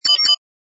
sfx_ui_react_accept02.wav